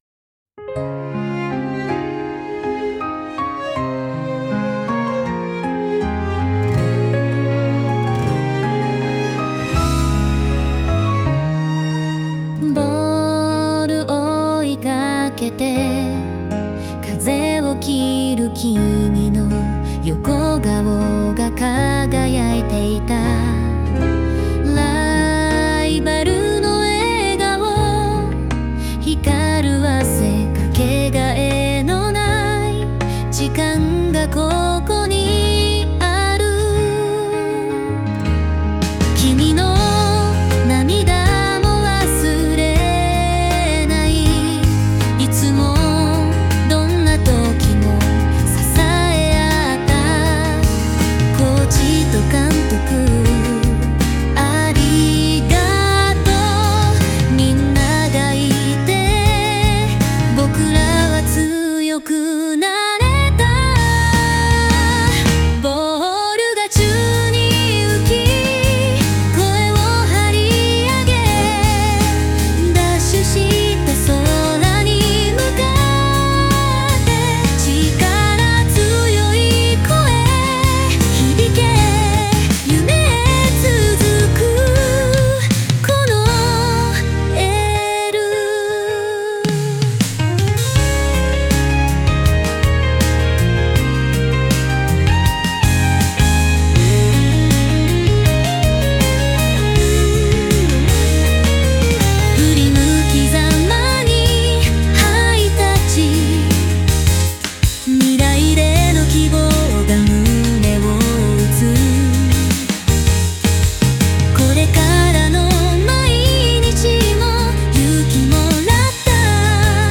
著作権フリーの無料楽曲です。女性ボーカルの歌になります。